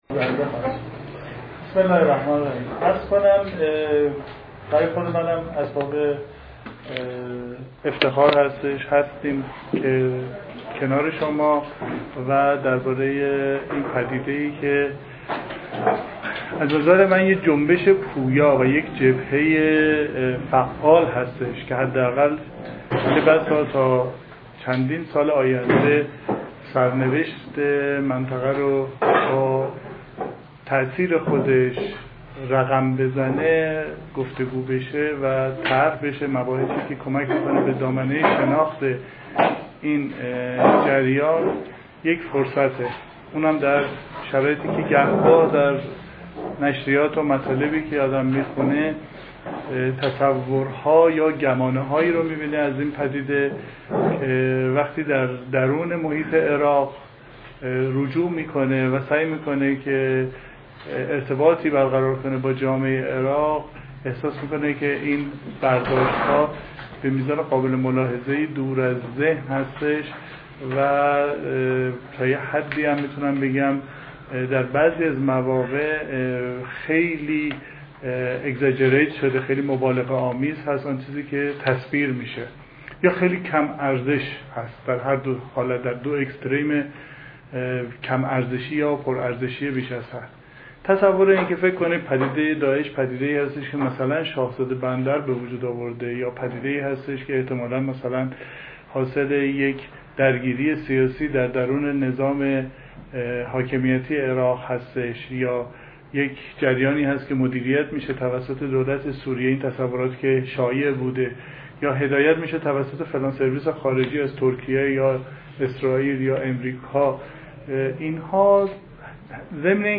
سخنرانی
در نشست «چگونگی شکلگیری داعش» است که در ۵ آبان ماه ۹۳ در انجمن علوم سیاسی برگزار شد.